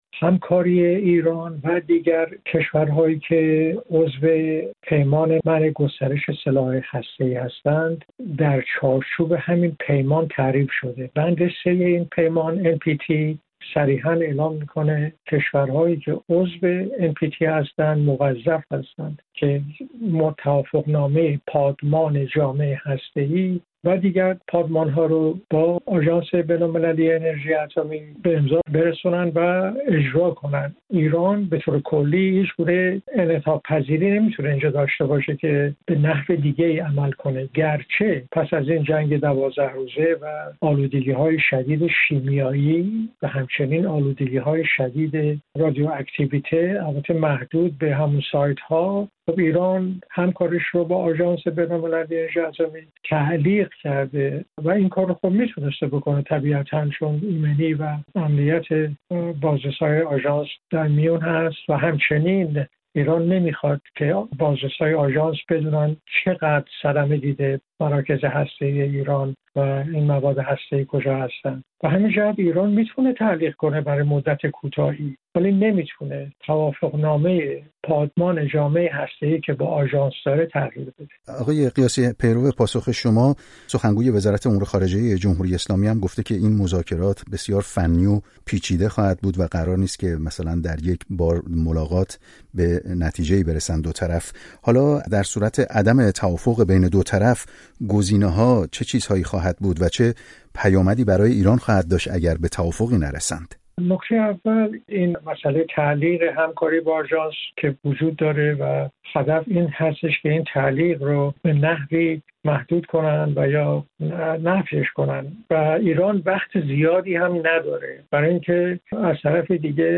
مذاکرات «پیچیده» آژانس با ایران در گفت‌وگو با استاد حقوق بین‌الملل هسته‌ای